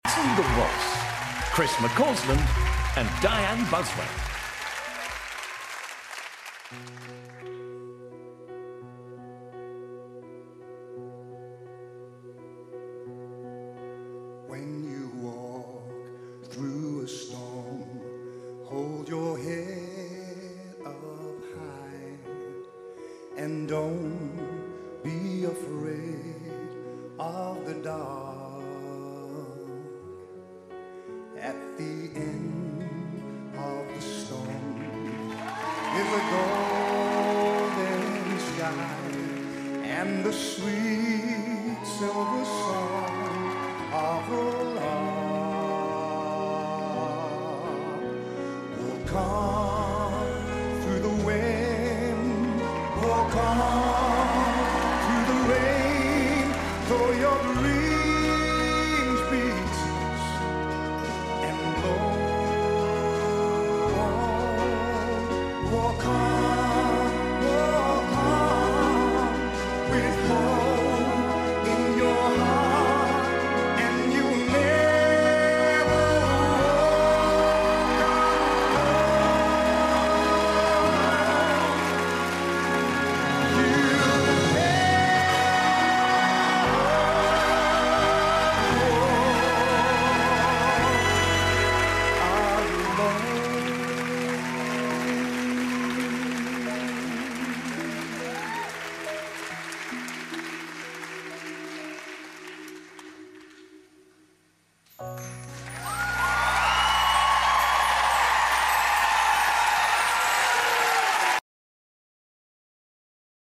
Chris and Dianne’s Waltz!